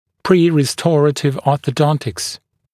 [ˌpriːrɪ’stɔrətɪvˌɔːθə’dɔntɪks][ˌпри:ри’сторэтивˌо:сэ’донтикс]ортодонтическая подготовка к реставрационному лечению